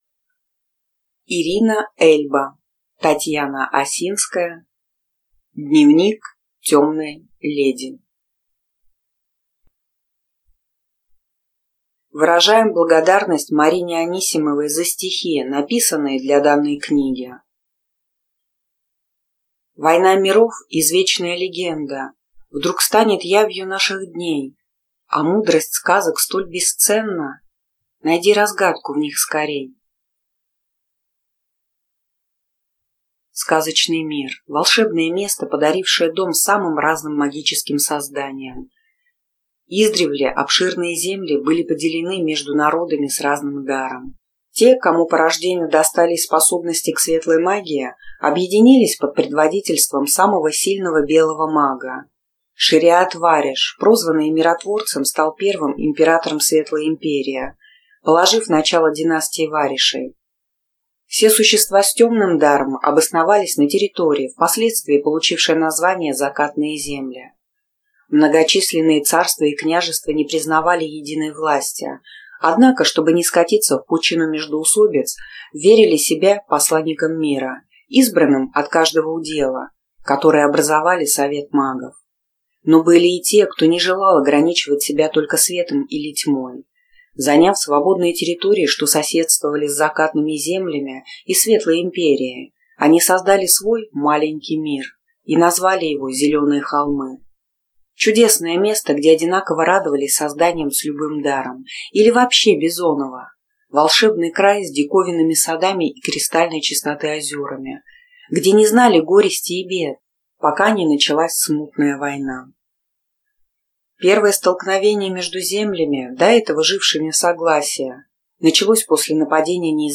Аудиокнига Дневник темной леди | Библиотека аудиокниг